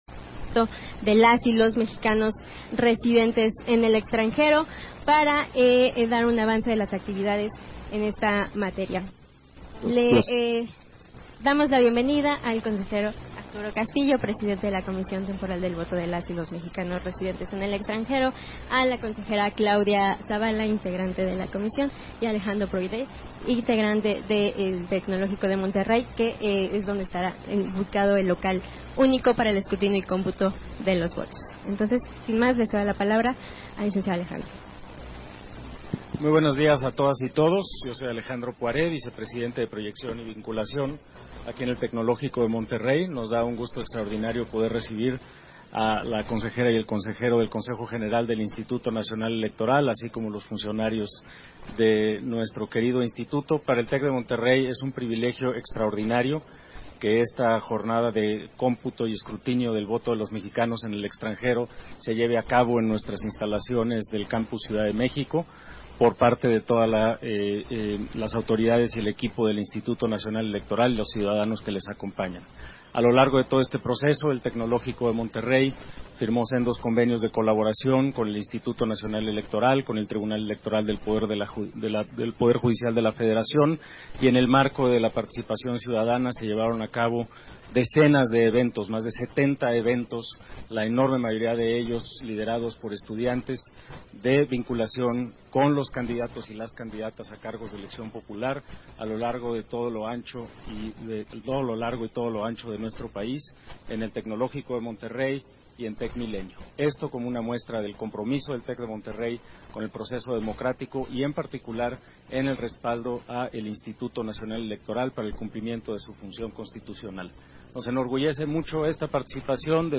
Conferencia de prensa del voto de las y los mexicanos residentes en el extranjero, con motivo de la jornada electoral 2024